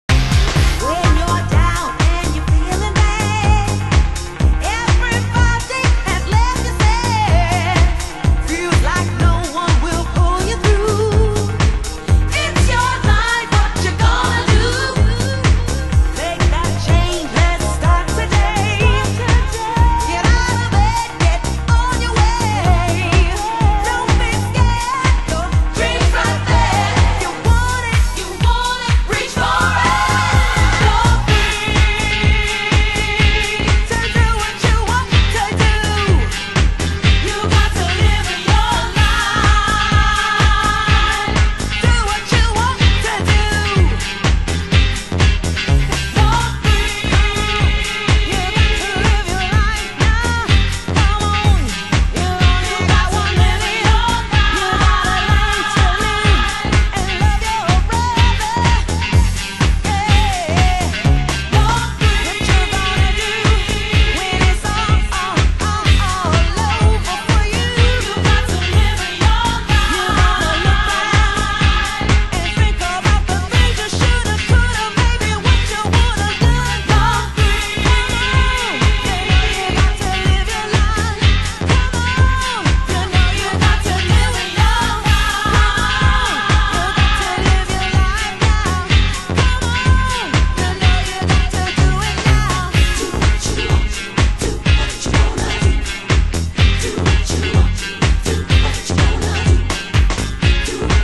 盤質：少しチリパチノイズ有